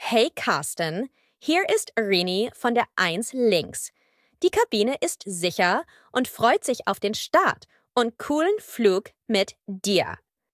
CallCabinSecureTakeoff.ogg